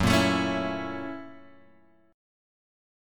F#7#9 chord {2 1 x 2 2 0} chord